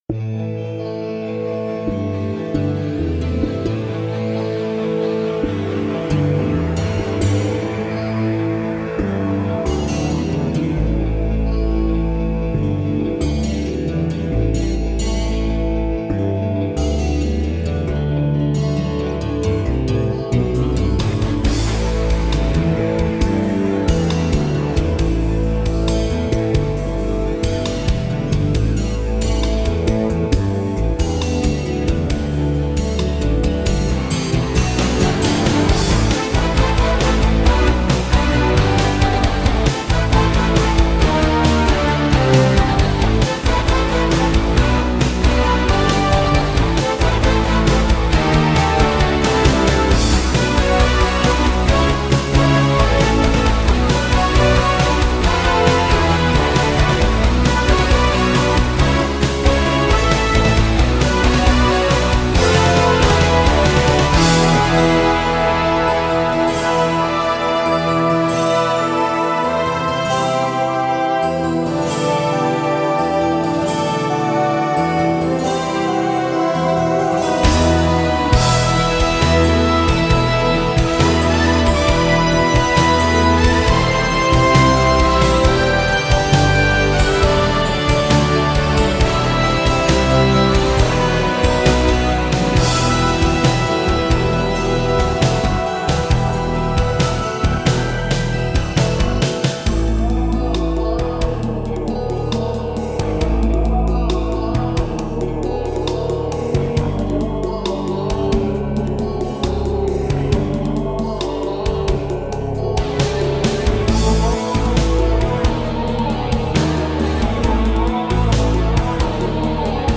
in-game music